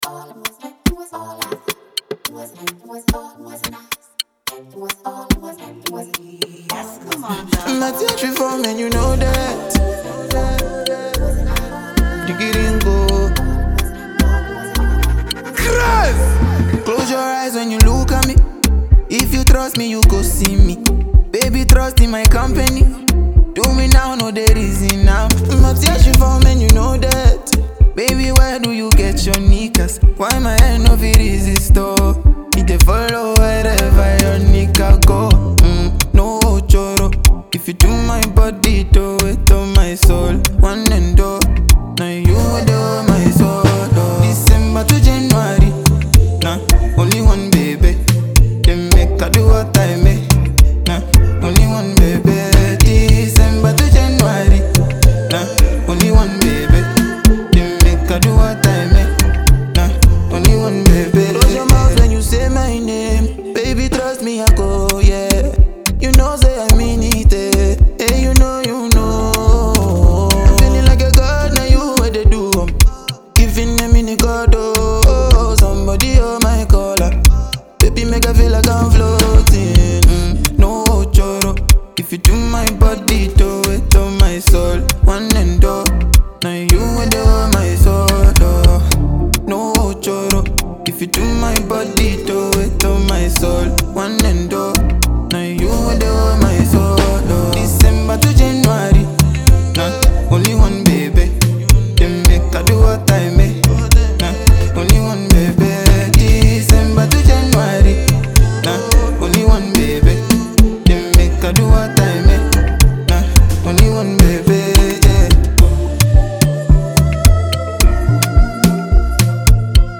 It’s built for dancing and singing along.